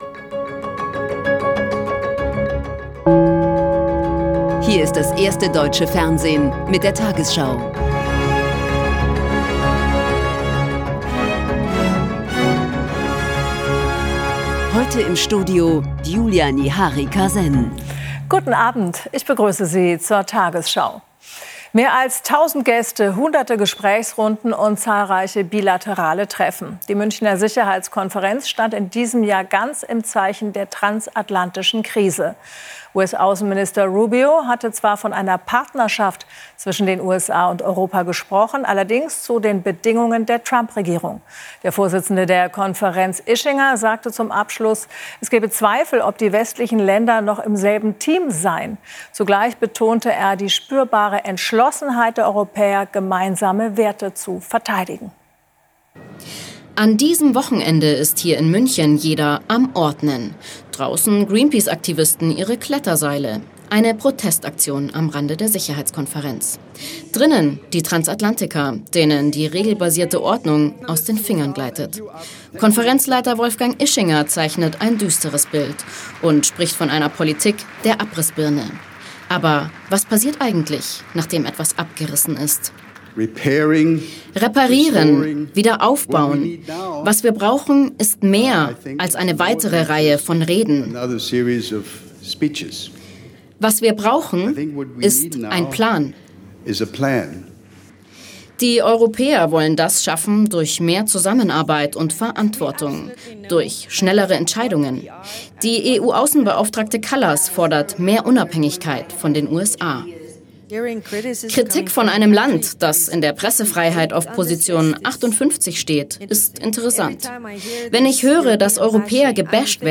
Die 20 Uhr Nachrichten (Audio)